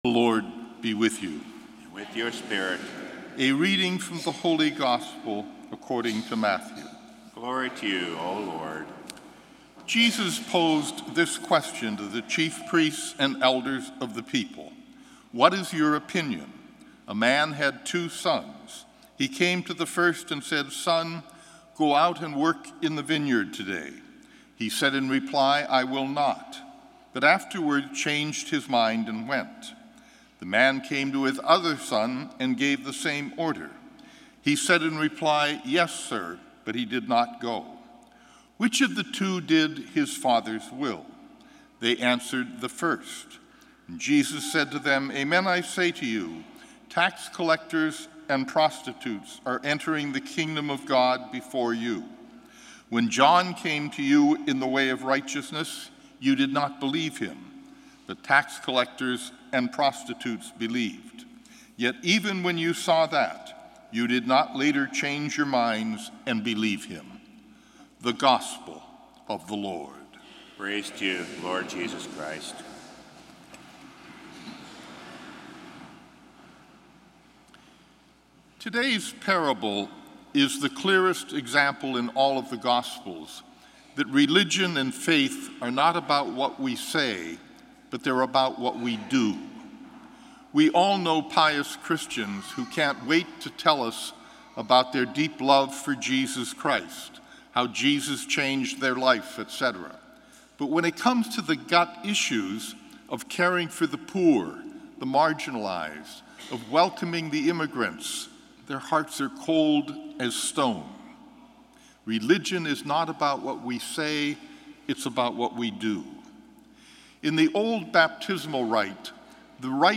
Gospel & Homily October 1, 2017